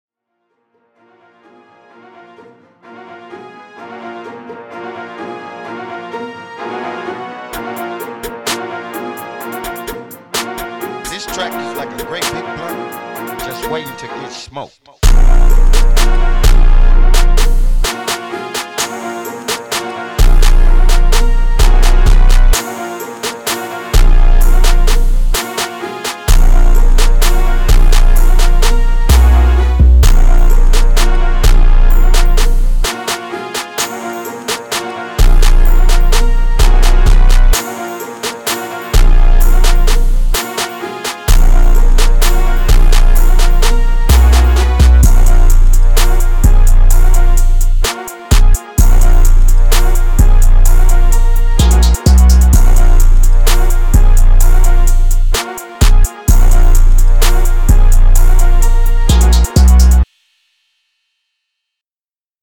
Жанр: hip-hop, trap
Hip-hop Dark 145 BPM